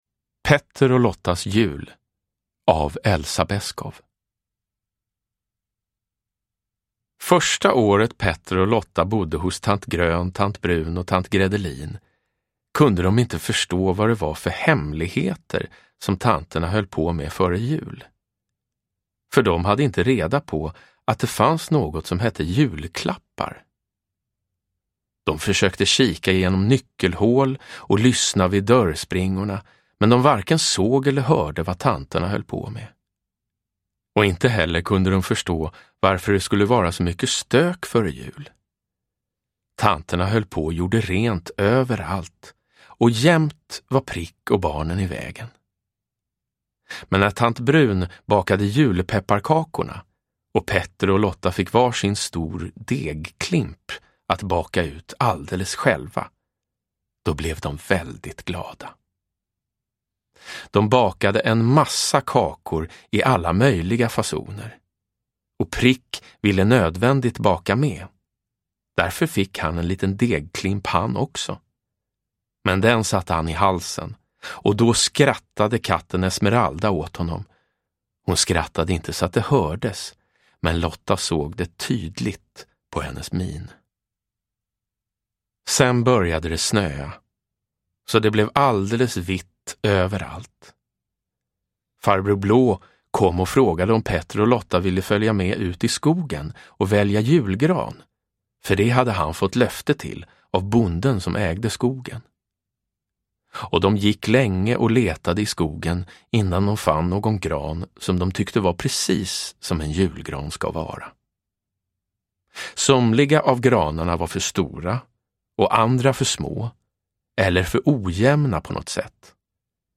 Petters och Lottas jul – Ljudbok
Uppläsare: Jonas Karlsson